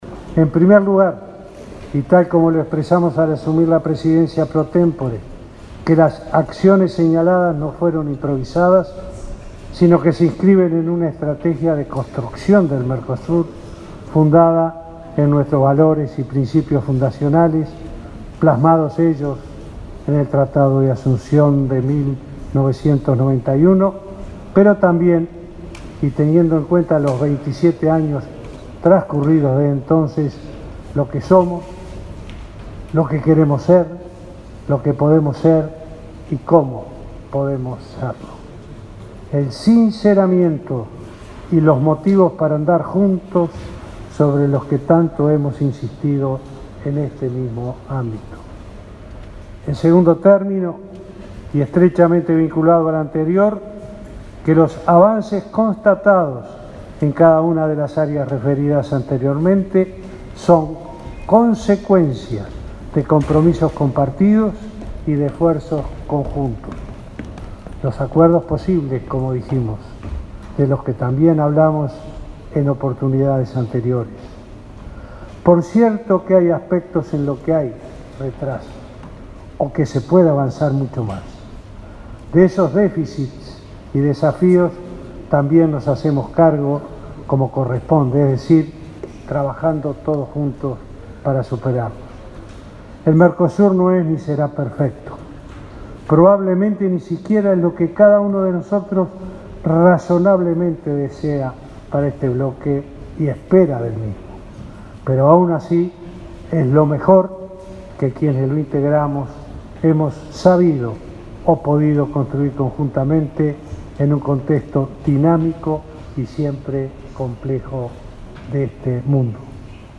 “Si la integración no es clave de ciudadanía y democracia no tiene sentido: queremos un Mercosur con contenido y con porvenir”, sostuvo el presidente Vázquez en la Cumbre de Presidentes del Mercosur, este martes 18 en Montevideo, en la que habló de la disminución de la pobreza, de las desigualdades en la región y de la ciudadanía como sistema de derechos y responsabilidades. También se refirió a acuerdos con otros bloques.